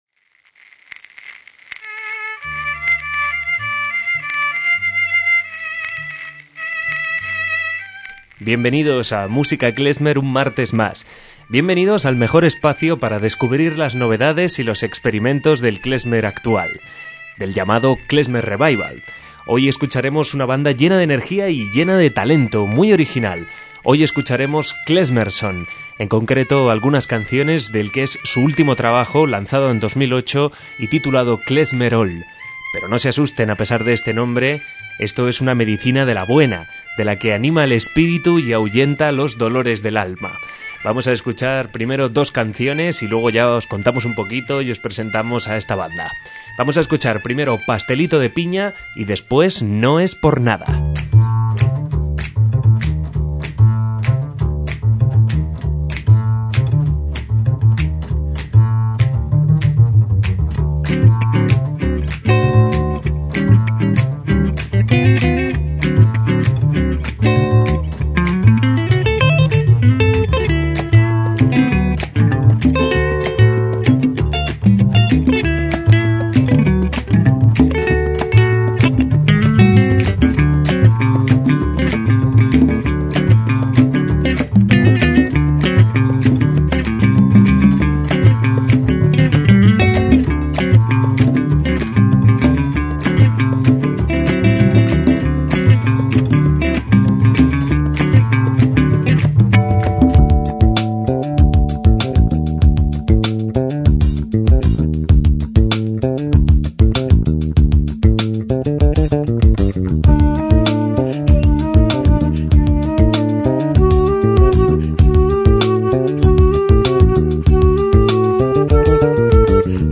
MÚSICA KLEZMER
guitarras y teclados
flauta y voz
jarana huasteca y percusiones